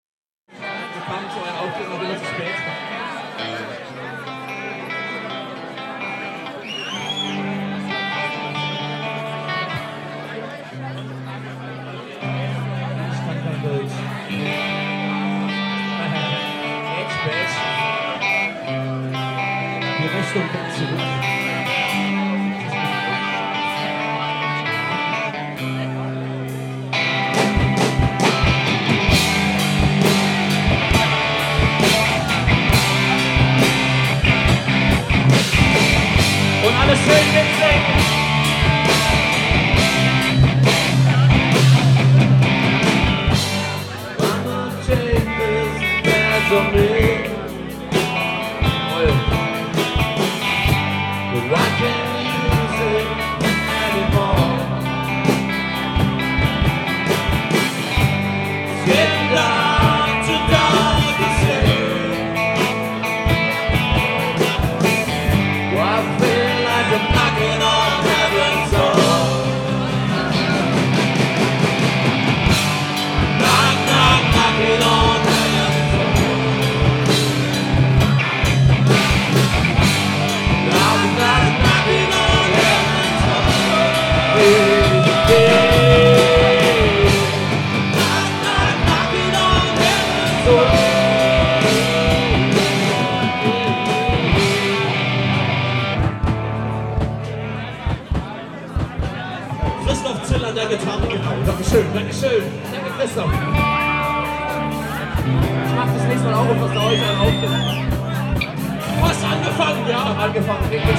am 03.06.2001 beim Pfingstturnier des Hockeyclub Gernsbach